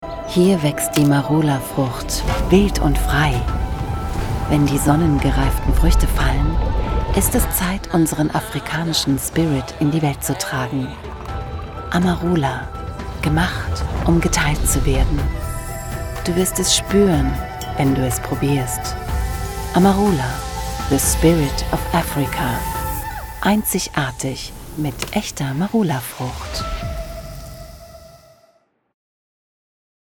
Erfahrene Profisprecherin
Marken Stimme